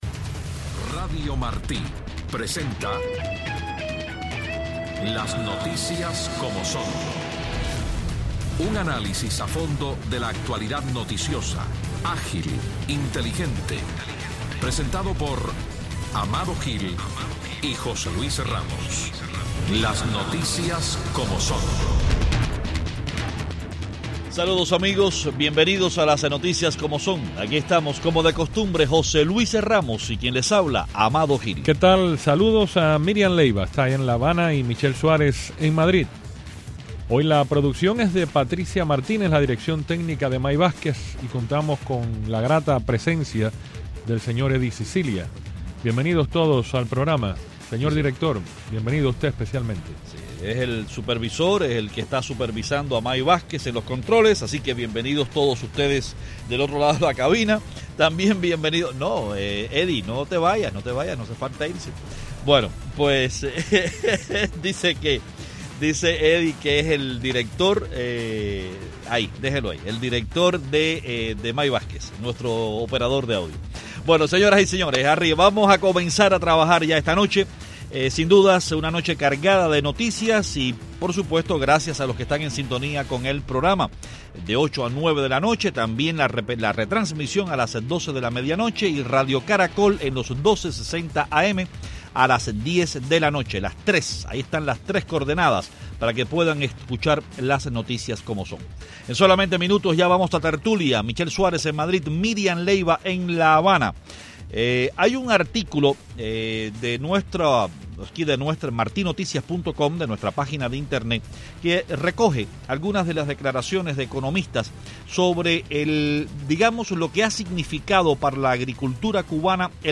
En tertulia